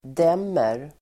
Uttal: [d'em:er]